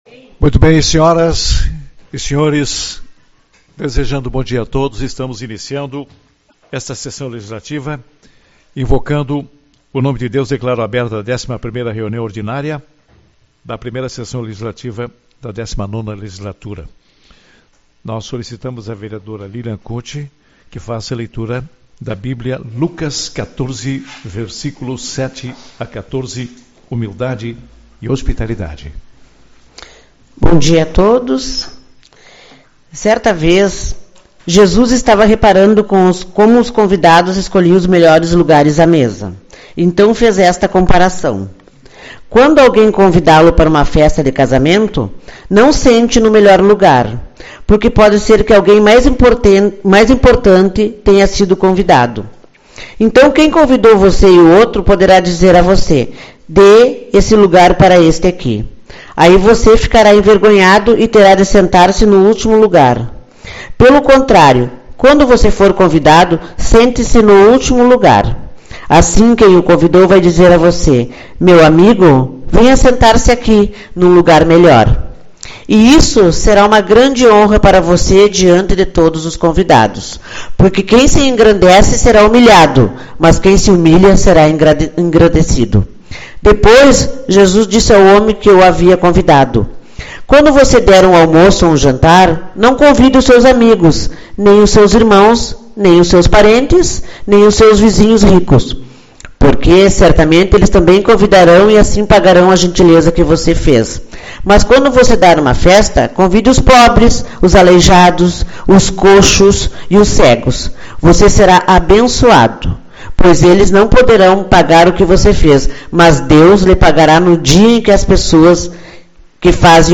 13/03 - Reunião Ordinária